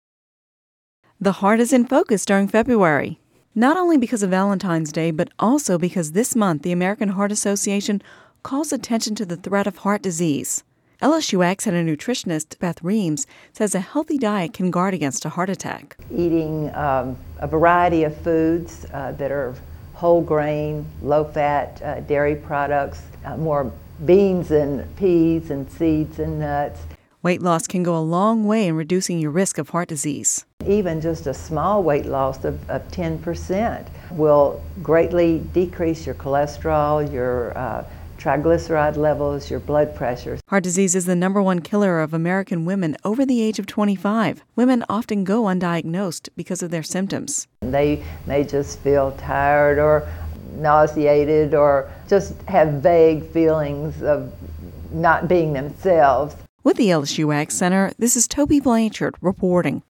(Radio News 02/14/11) The heart is in focus during February, not only because of Valentine’s Day but also because this month the American Heart Association calls attention to the threat of heart disease.